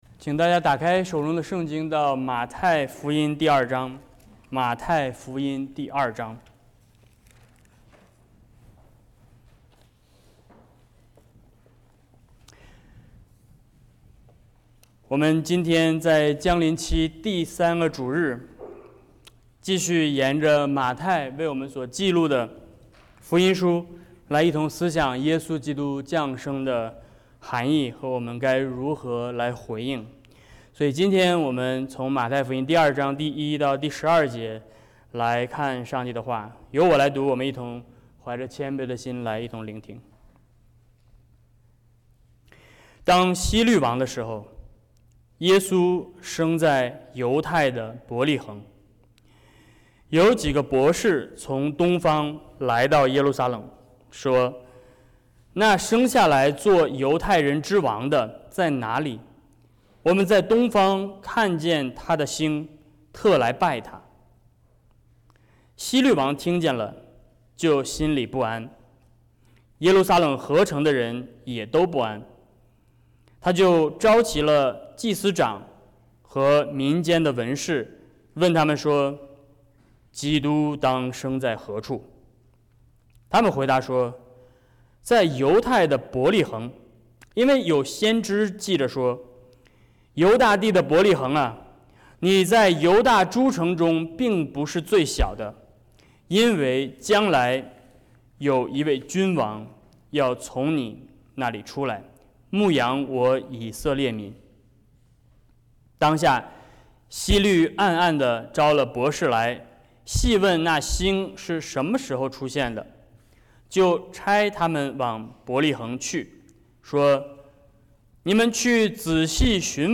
Service Type: Sermons 12月15日｜主日50 圣道宣讲： 马太福音2:1-12 ｜三种回应 本周讲道讨论问题： 1. 马太记录了哪三种对耶稣降生的回应？